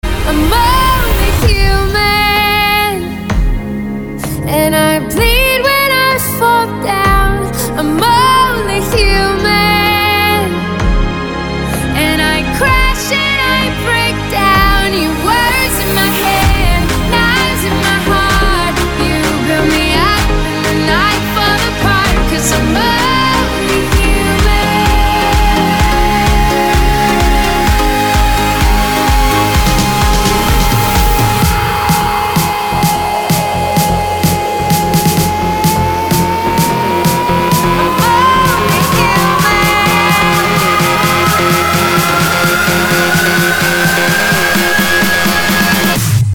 • Качество: 256, Stereo
красивые
женский вокал
dance
спокойные
vocal